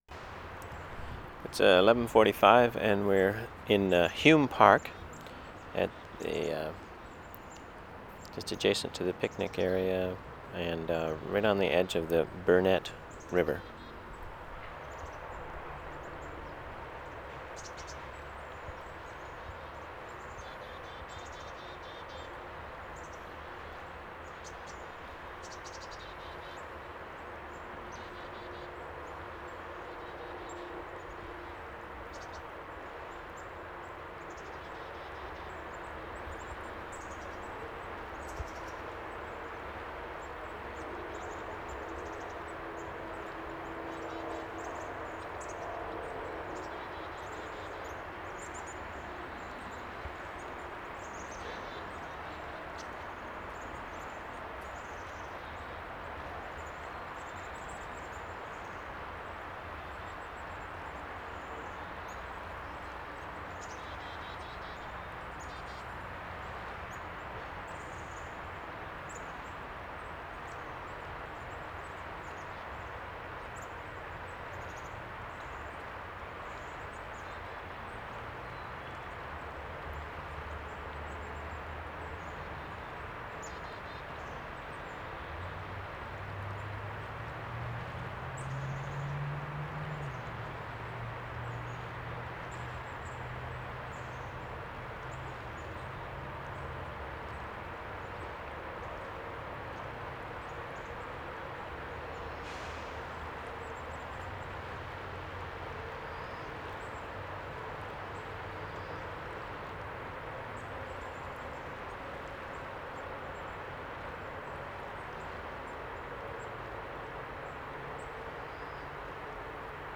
VANCOUVER SOUNDSCAPE, JUNE, 1996
Hume Park, Brunette River 5:25